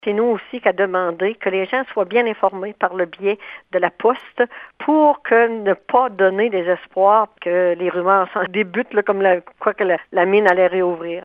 Délisca Ritchie-Roussy, affirme avoir été informée des forages menés par Glencore ainsi que la population qui a été avisée afin d’éviter que des rumeurs commencent à circuler sur la réouverture possible de la mine de cuivre :